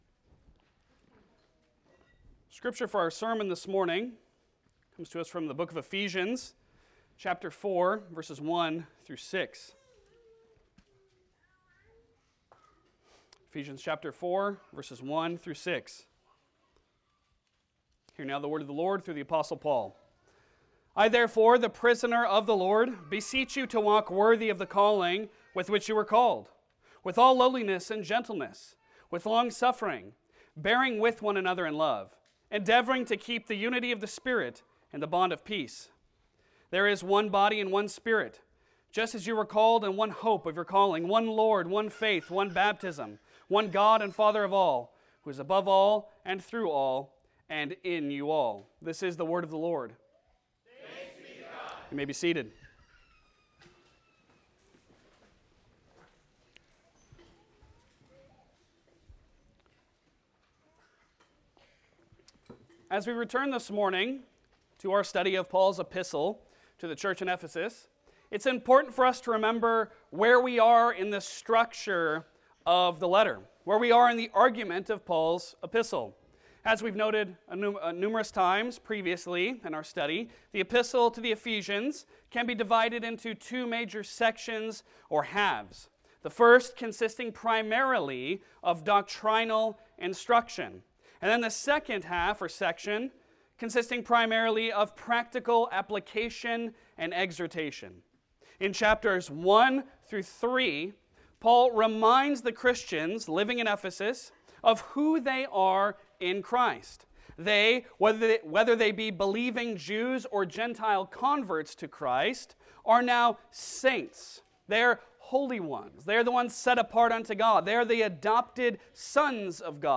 Passage: Ephesians 4:1-6 Service Type: Sunday Sermon